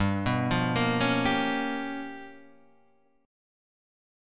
G 7th Sharp 9th Flat 5th